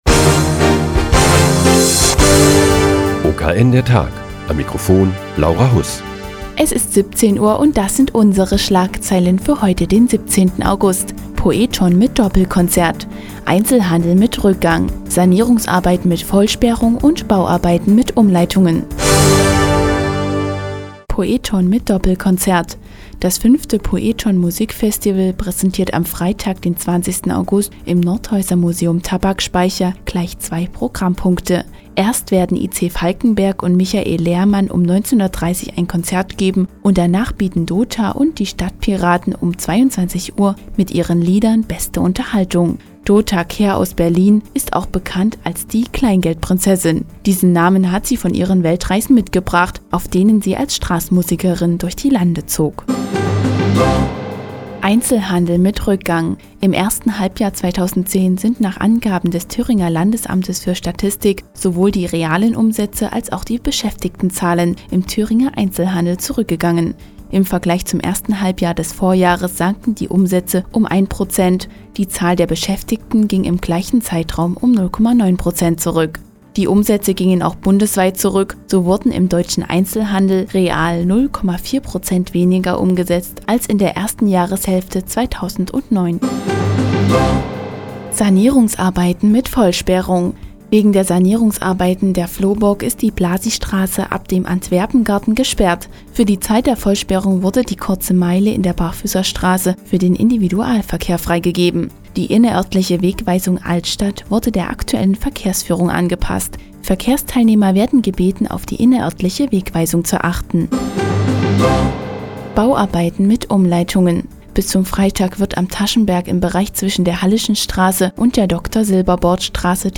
Die tägliche Nachrichtensendung des OKN ist nun auch in der nnz zu hören. Heute geht es um ein Doppelkonzert zum "poeton Musikfestival" und die Vollsperrung der Blasii- Straße ab dem Antwerpen- Garten.